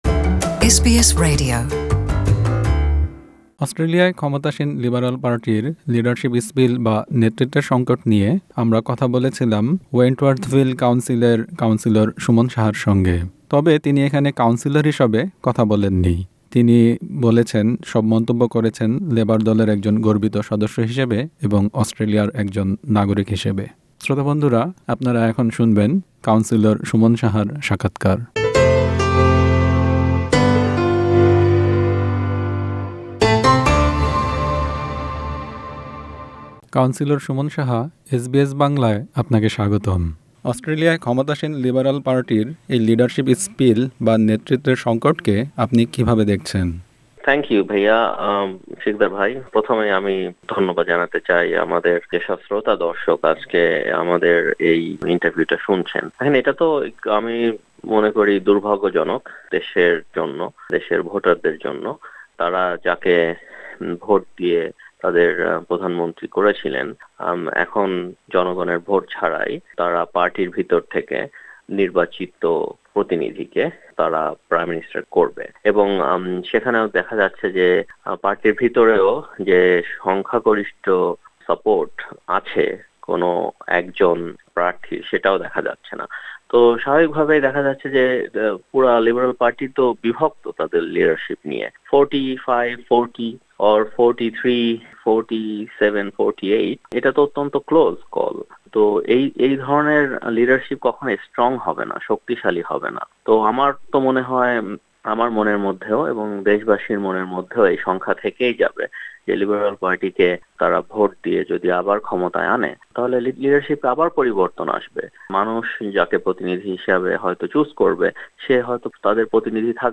অস্ট্রেলিয়ায় নেতৃত্বের পালা বদল: কাউন্সিলর সুমন সাহার সাক্ষাৎকার
অস্ট্রেলিয়ায় লিডারশিপ স্পিল নিয়ে এসবিএস বাংলার সঙ্গে কথা বলেছেন কাম্বারল্যান্ড কাউন্সিলের ওয়েন্টওয়ার্থভিল ওয়ার্ডের কাউন্সিলর সুমন সাহা।